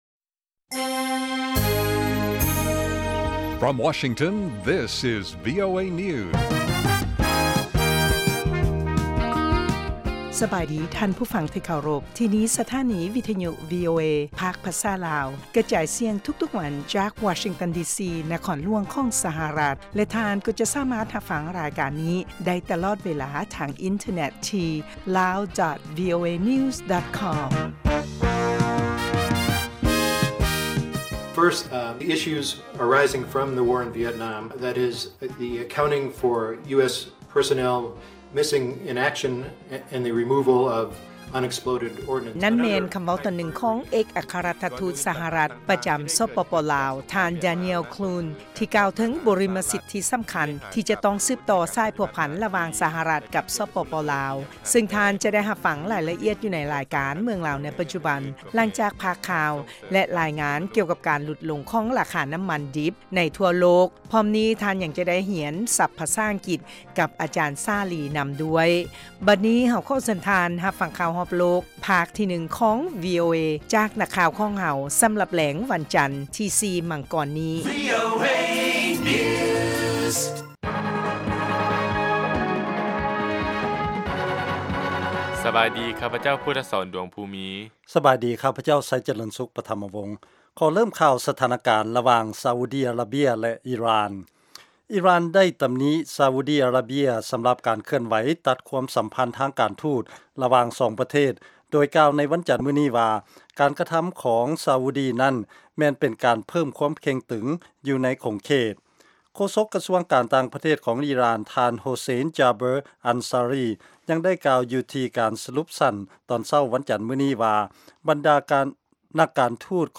ລາຍການກະຈາຍສຽງຂອງວີໂອເອ ລາວ
ວີໂອເອພາກພາສາລາວ ກະຈາຍສຽງທຸກໆວັນ ເປັນເວລາ 30 ນາທີ.